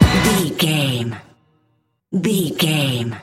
Fast paced
Ionian/Major
Fast
synthesiser
drum machine
80s